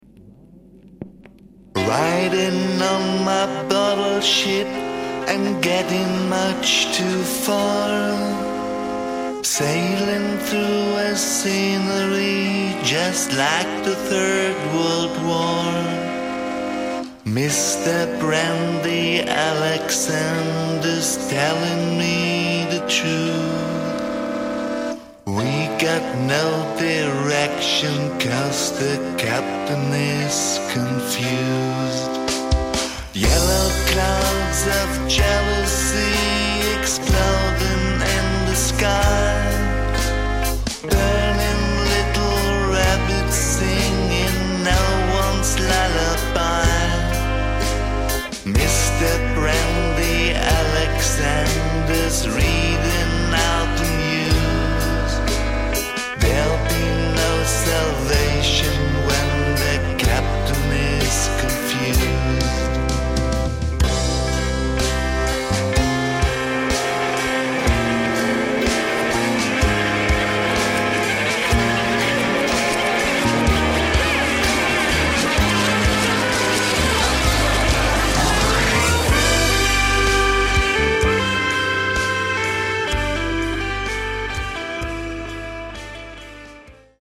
ld-voc., org.
voc., rh-g., horn
voc., bg., rubab
bassoon